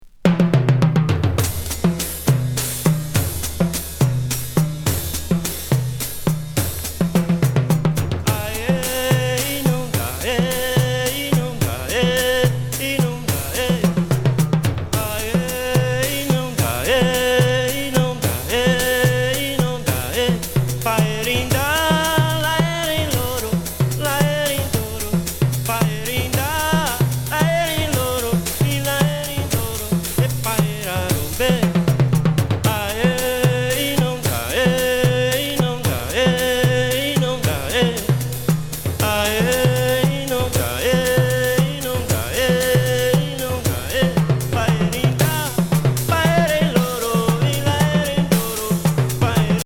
ミニマム・レイヤー・パーカッションと天界交信なアカペラ・コーラス！